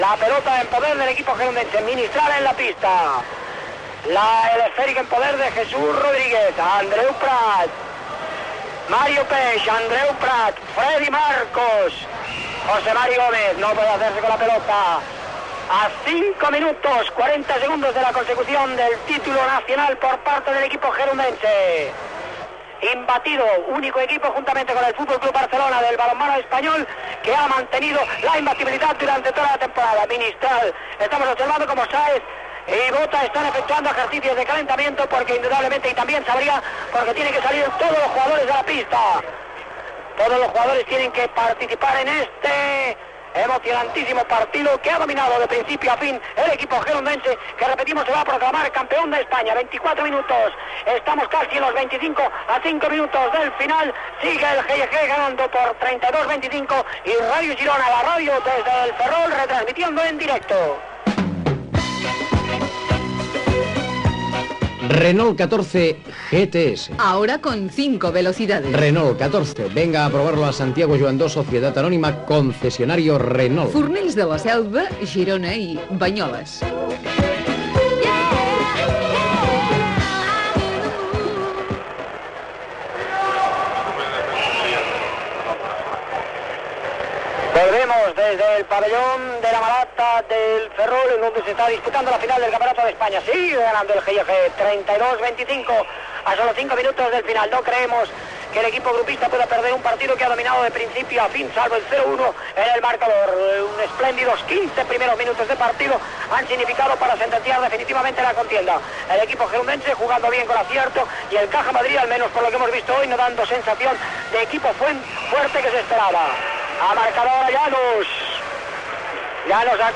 1f1762abbe31424b8d65c1e9909c6b49a9b07710.mp3 Títol Ràdio Girona 2 Emissora Ràdio Girona 2 Cadena SER Titularitat Privada estatal Descripció Retransmissió del partit d'handbol corresponent a la final del campionat d'Espanya de primera divisió d'handbol masculí entre el Grup Excursionista i Esportiu Gironí (GEiEG) i el Cajamadrid, celebrat al Ferrol.